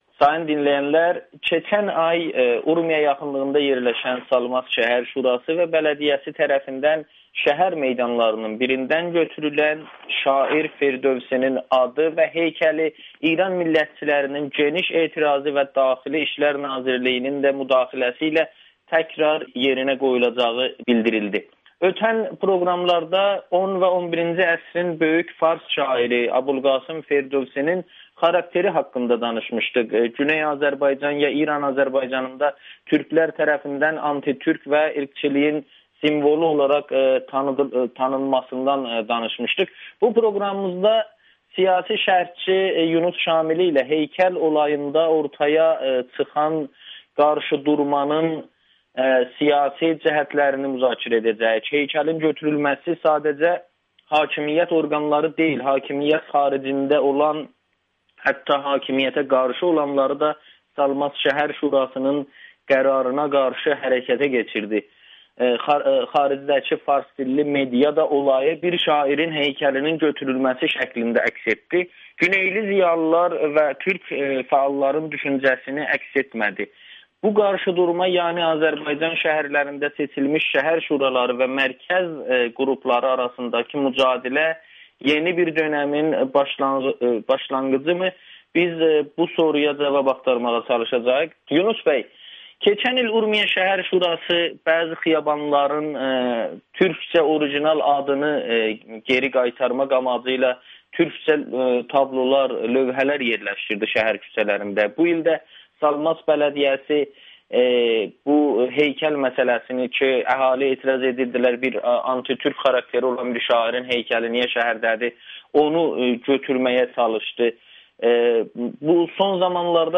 Salmasda heykəl olayı və güneyin şəhər şuraları [Audio-Müsahibə]